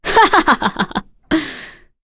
Maria-Vox_Happy2.wav